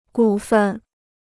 股份 (gǔ fèn) Free Chinese Dictionary